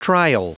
Prononciation du mot trial en anglais (fichier audio)
Prononciation du mot : trial